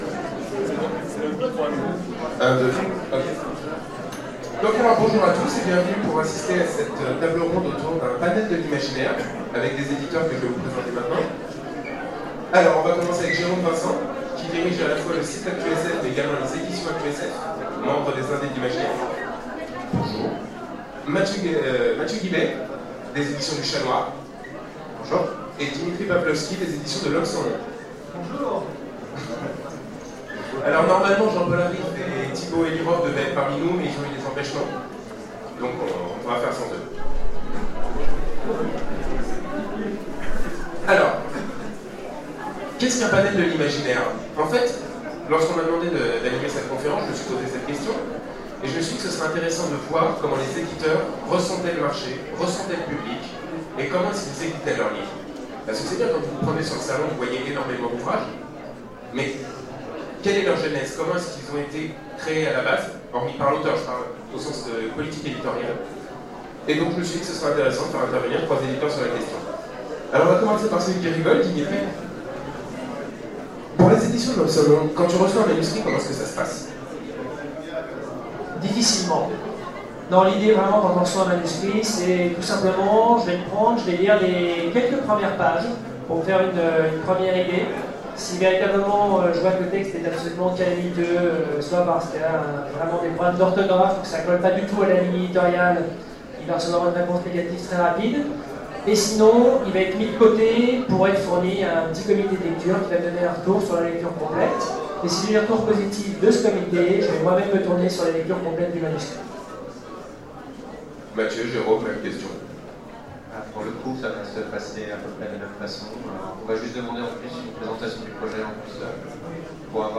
Salon Fantastique 2016 : Conférence Panomrama de l'imaginaire français